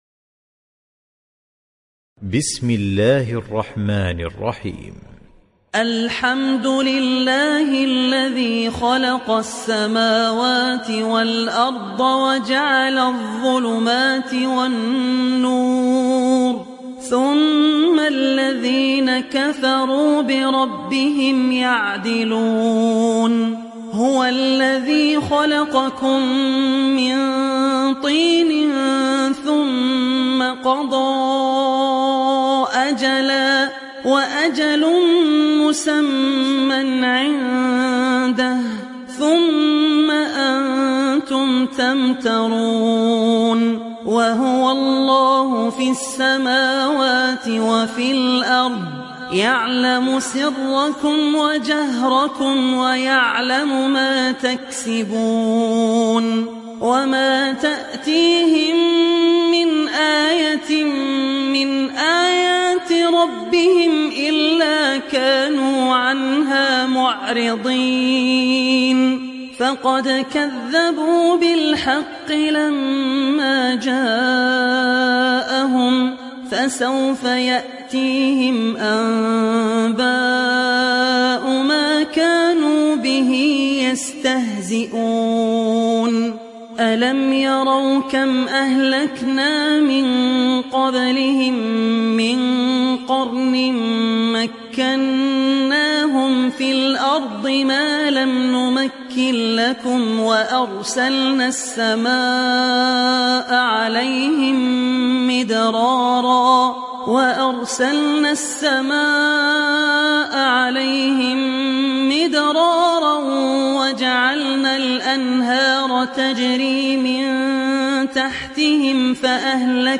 تحميل سورة الأنعام mp3 بصوت عبد الرحمن العوسي برواية حفص عن عاصم, تحميل استماع القرآن الكريم على الجوال mp3 كاملا بروابط مباشرة وسريعة